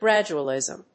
音節grád・u・al・ìsm 発音記号・読み方
/‐lìzm(米国英語)/